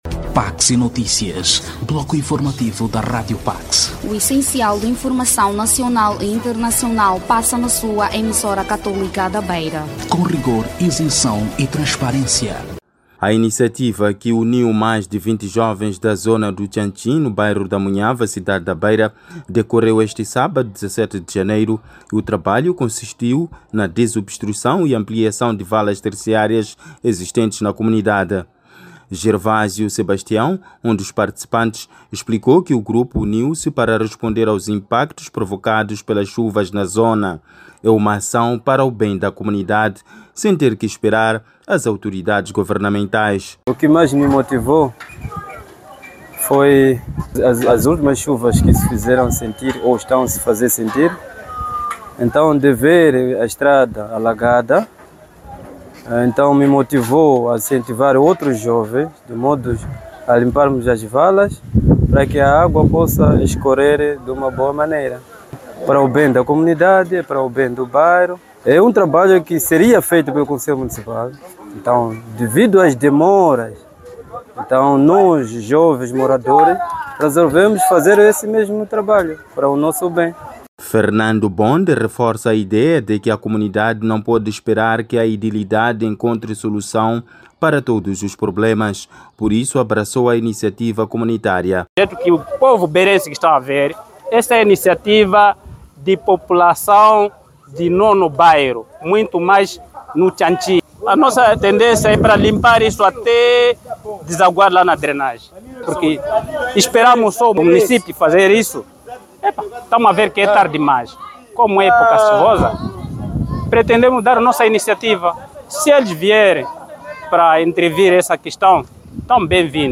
Cientes de que muitos bairros no país tem estado a sofrer os impactos da época chuvosa, os nossos entrevistados apelam os residentes para seguirem o seu exemplo, colocar a mão na massa para o bem da própria comunidade.